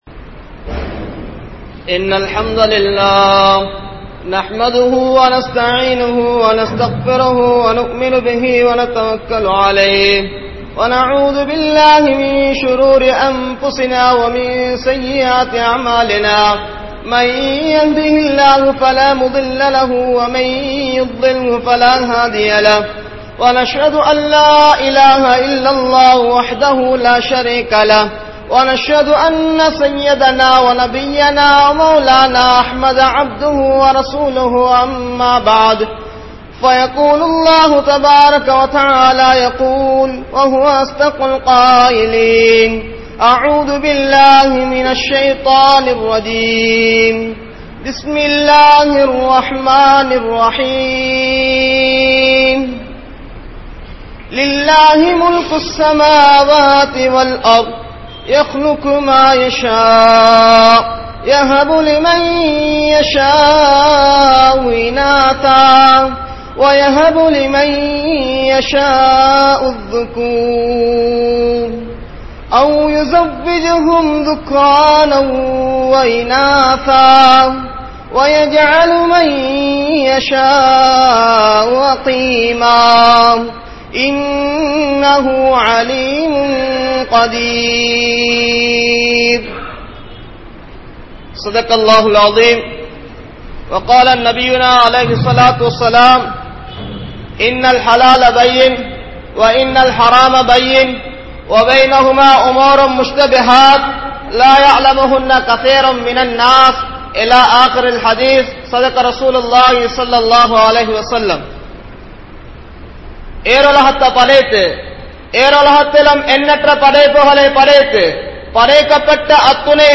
Shaalihaana Pillaihal (சாலிஹான பிள்ளைகள்) | Audio Bayans | All Ceylon Muslim Youth Community | Addalaichenai
Mallawapitiya Jumua Masjidh